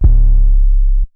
DRO 808-24b.wav